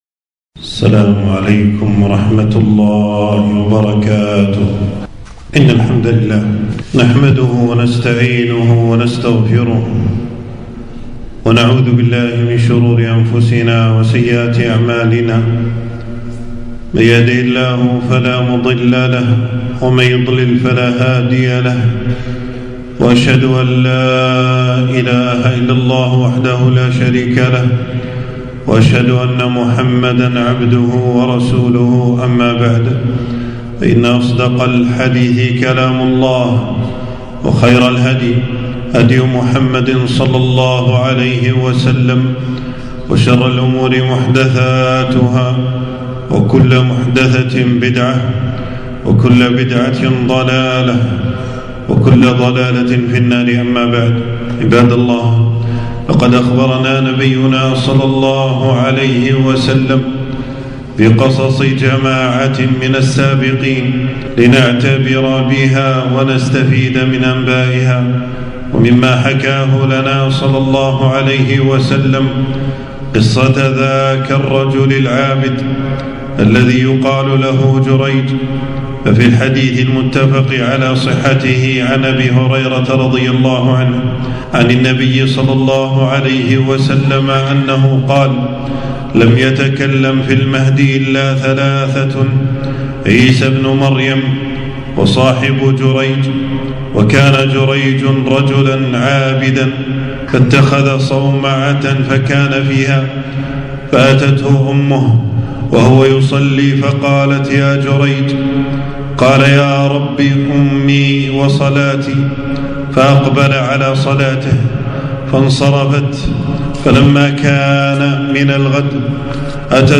خطبة - قصة جريج العابد وما فيها من الفوائد